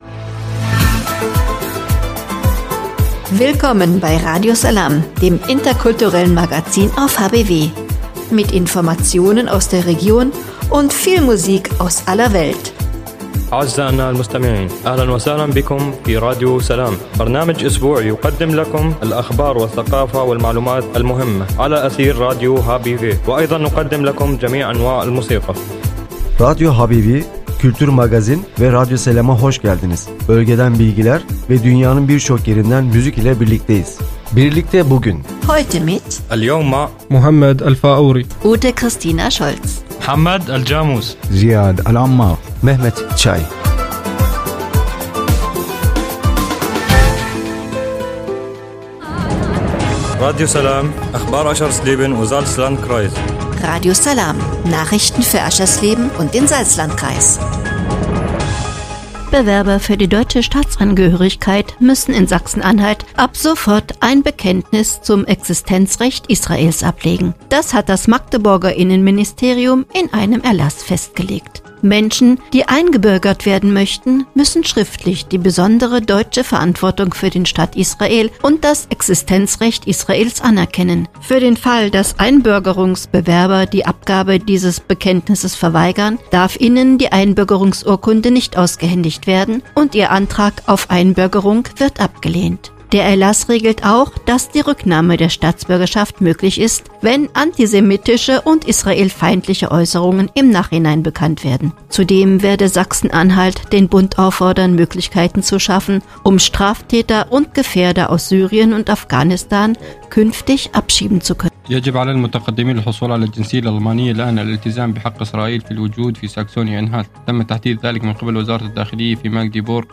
„Radio Salām“ heißt das interkulturelle Magazin auf radio hbw.
(Hinweis: Die in der Sendung enthaltene Musik wird hier in der Mediathek aus urheberrechtlichen Gründen weggelassen.)